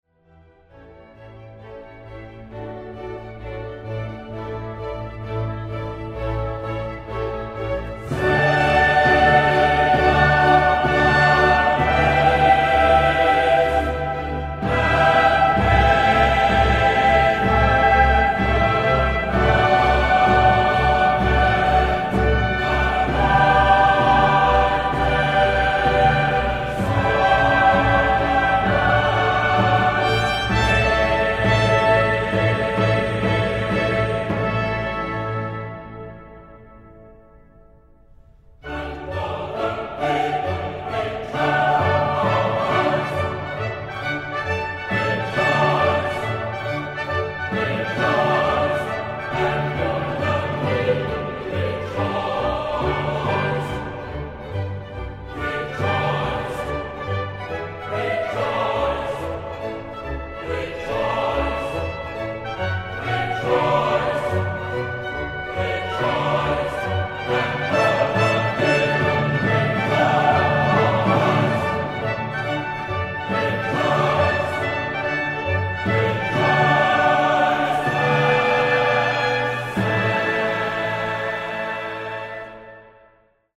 La apertura de Zadok, el sacerdote de Händel, desarrolla un coro, seguido por la orquesta y precedida por una fanfarria de tres trompetas y es especialmente conocida por el público en general, ya que inspiró al Himno de la Liga de Campeones de la UEFA.